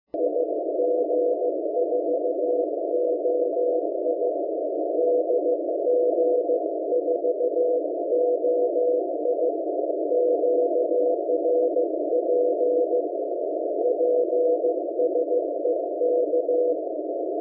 Включил напрямую на балконную mfj-1620 под SDR Console
На удивление, без всяких преселекторов неплохо звучит.
Имя файла: 2025-03-08T160006Z14.182.8MHzUSB.mp3